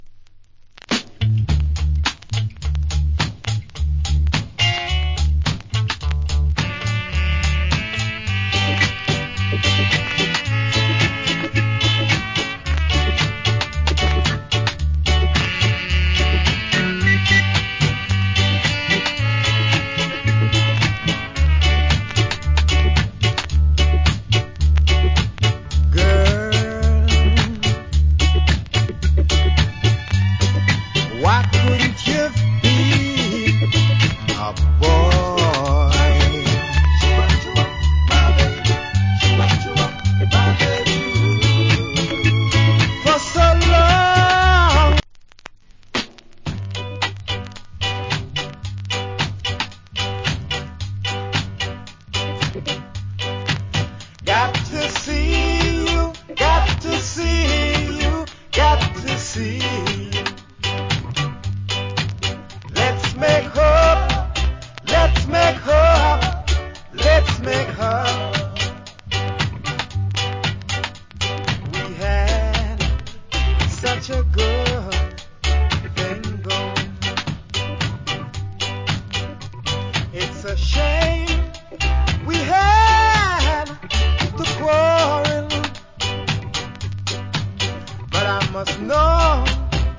コメント Nice Early Reggae Vocal. / Good Early Reggae Vocal.